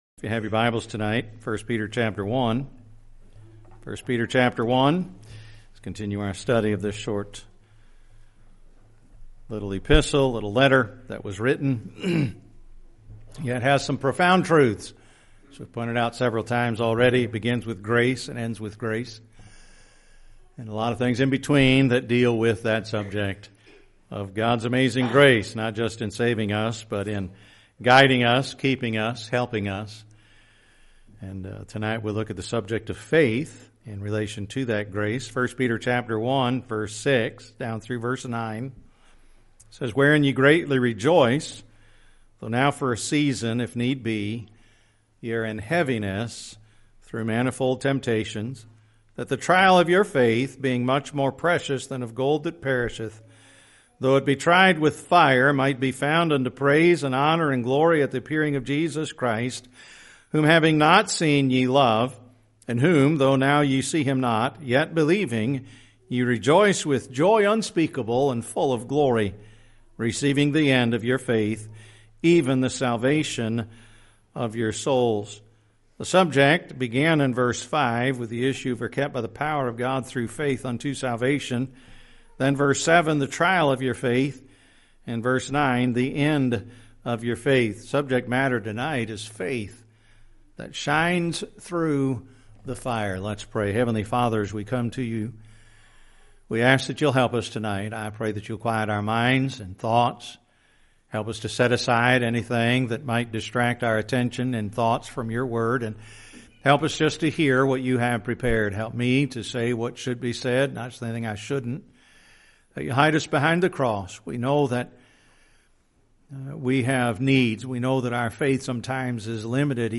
Sunday evening